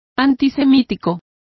Also find out how antisemiticos is pronounced correctly.